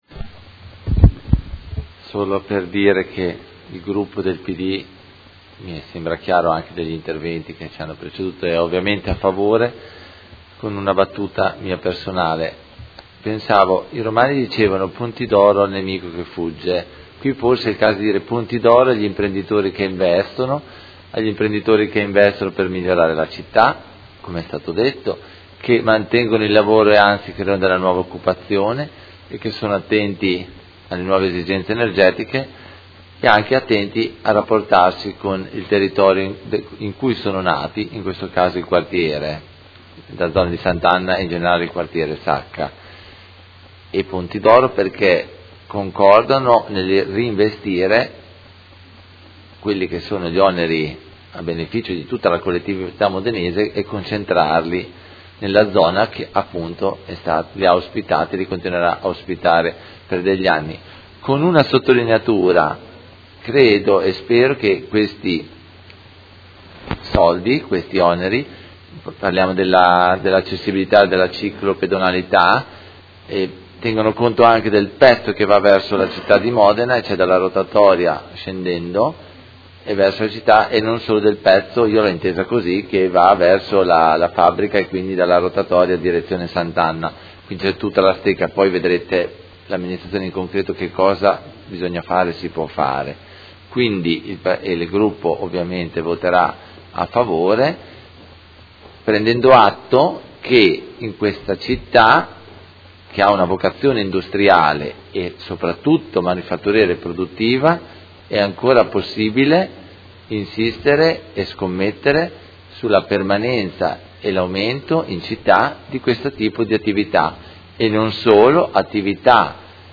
Seduta del 27/10/2016 Dichiarazione di voto.